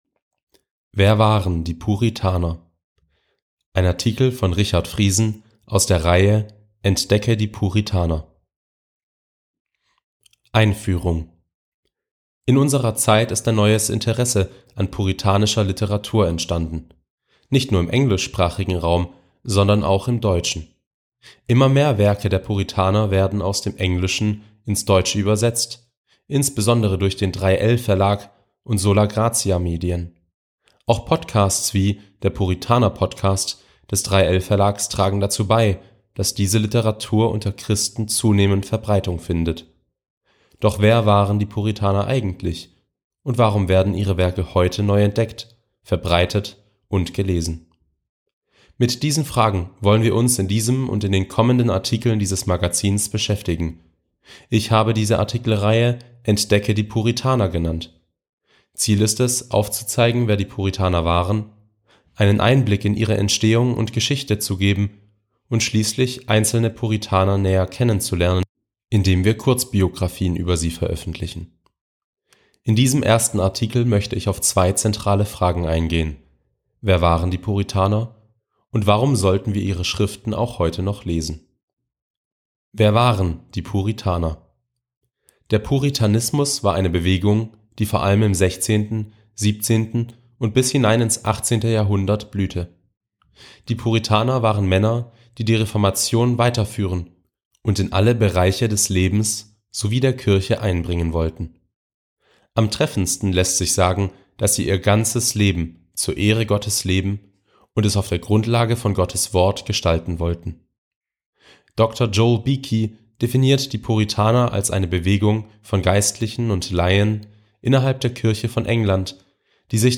Hörartikel ~ Allein das Wort